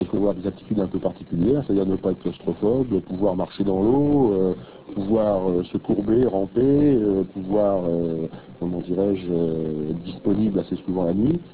Extracts from France Inter radio show "Les Visiteurs du Noir"